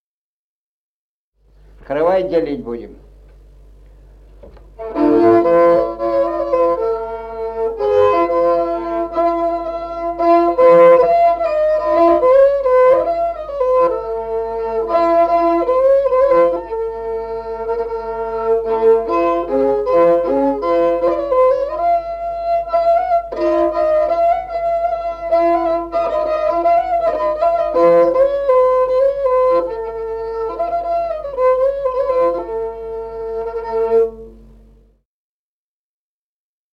Музыкальный фольклор села Мишковка «Каравай делить будем», репертуар скрипача.